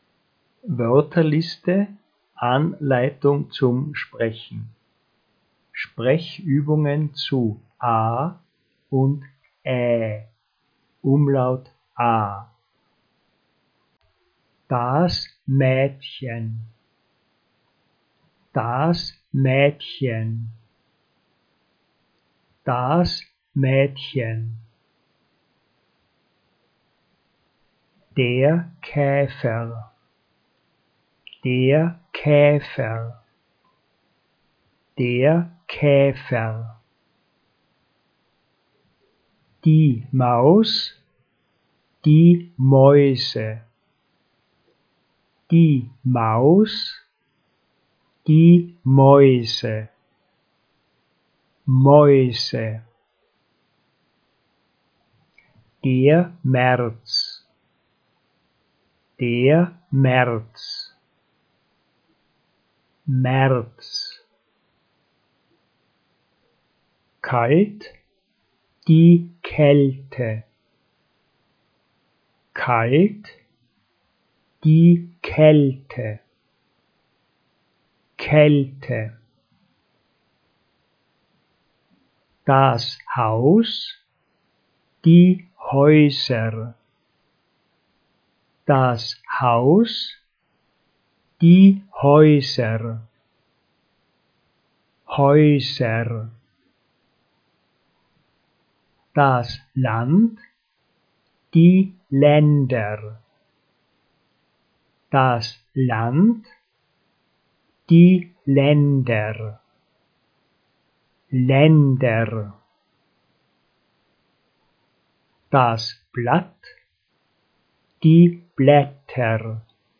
Sprechübungen zu „A“ und „Ä“ – „Umlaut A“.